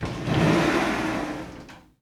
Glass Shower Door Open Sound
household
Glass Shower Door Open